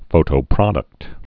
(fōtō-prŏdəkt)